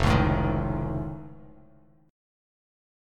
Listen to F+7 strummed